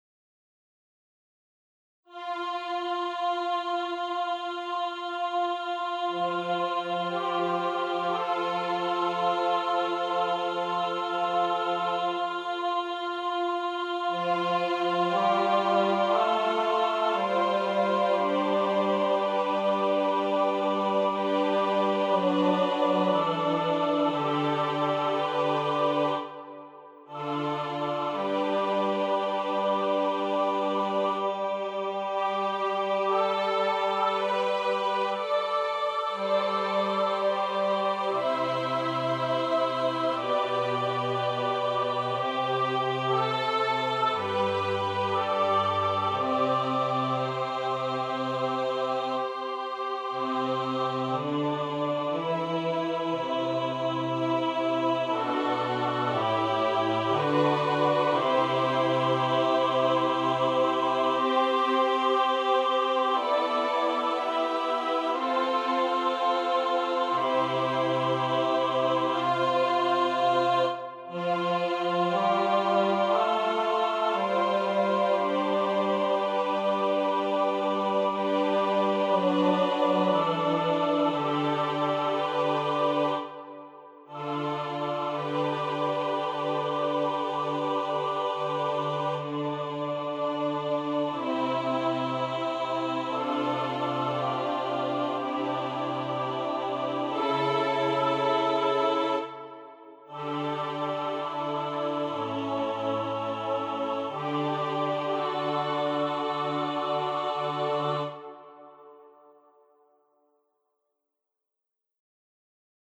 • Music Type: Choral
• Voicing: SATB
• Accompaniment: a cappella